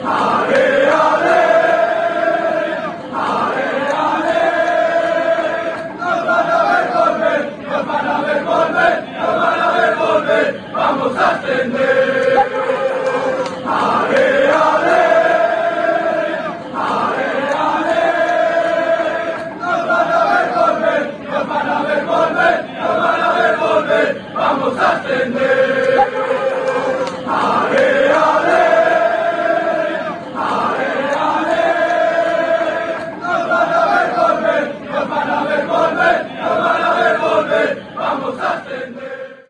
Como si estuvieras en el estadio!